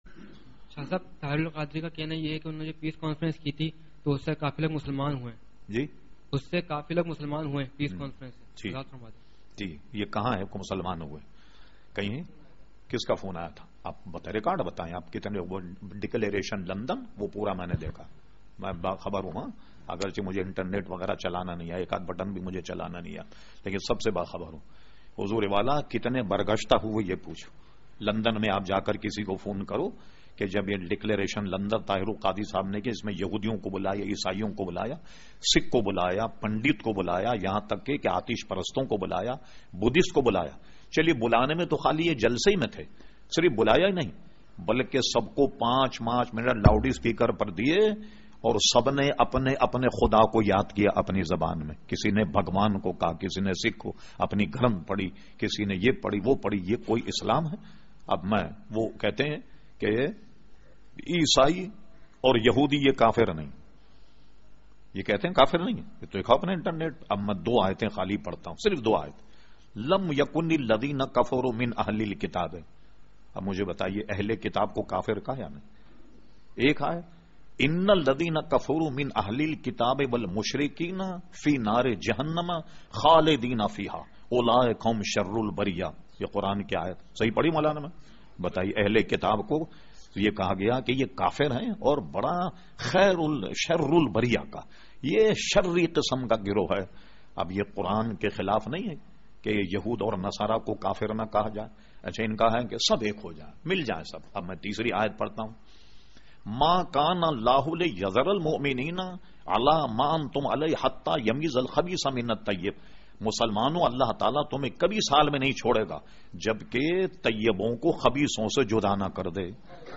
Q/A Program held on Sunday 01 Janruary 2012 at Masjid Habib Karachi.